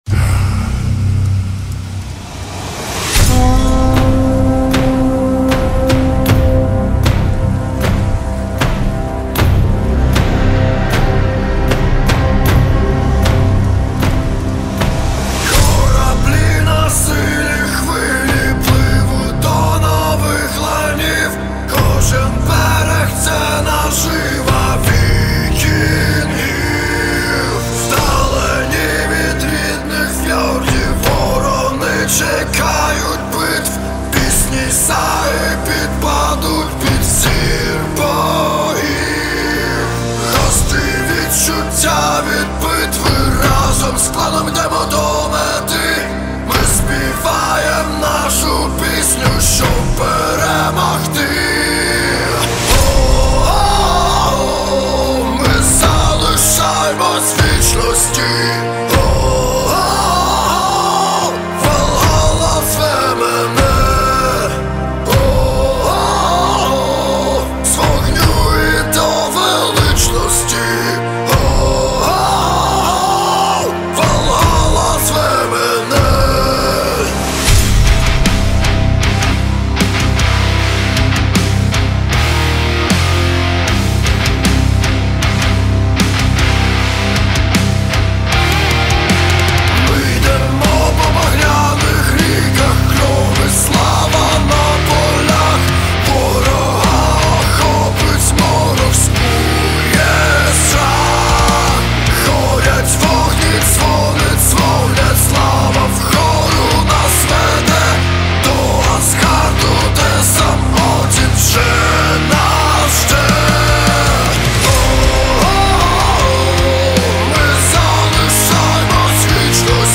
• Качество: 320 kbps, Stereo
Viking/Nordic/Dark Folk Music